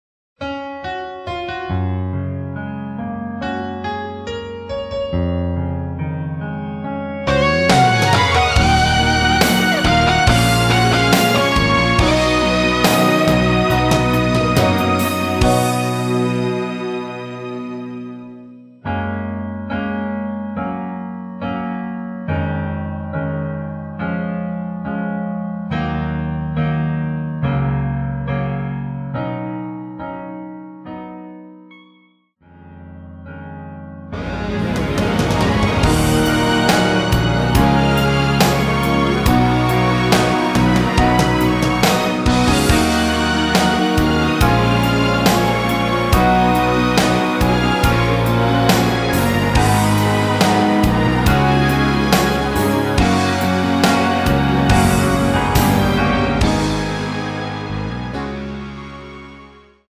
MR입니다.